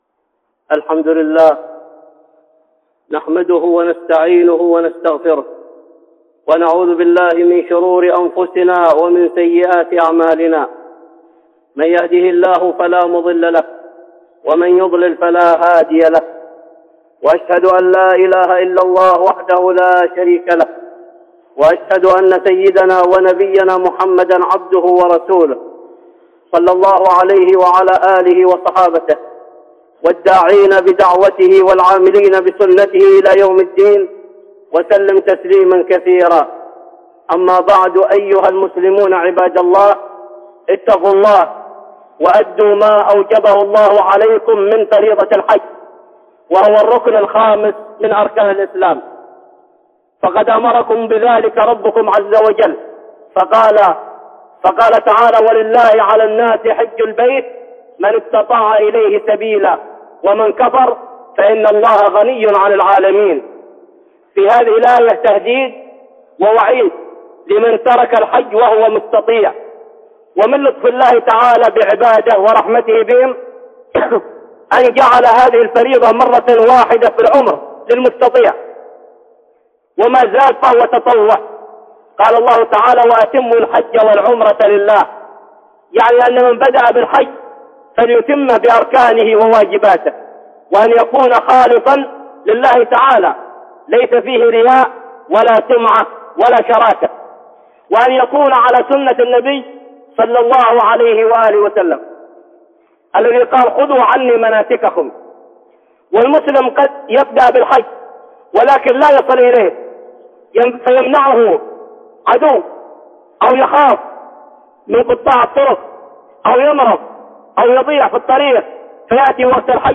(خطبة جمعة) تفسير آيات من الحج